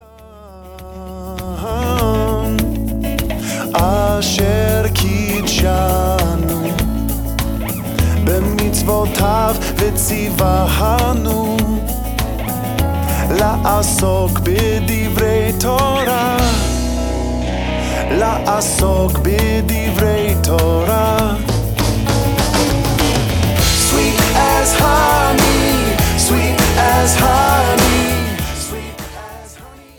hooky intellectual rock
All cuts are remastered